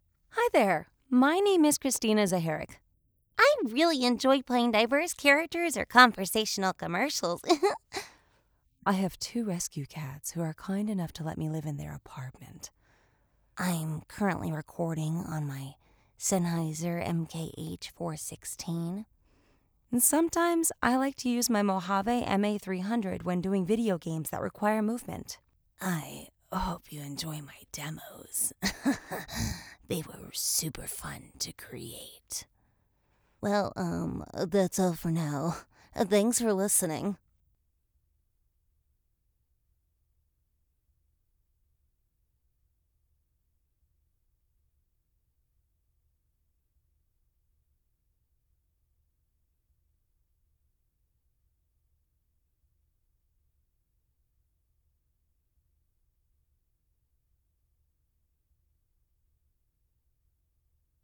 Raw-Studio-Audio.wav